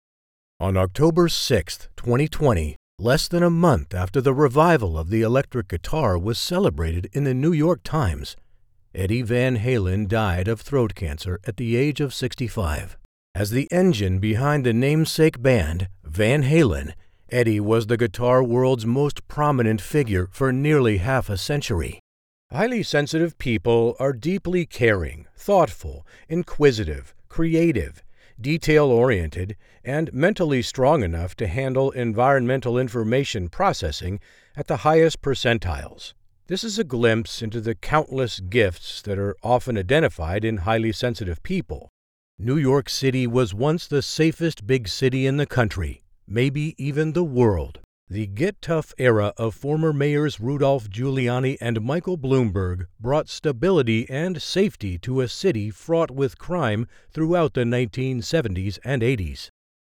Male
Adult (30-50), Older Sound (50+)
Narration
Documentary - Non-Fiction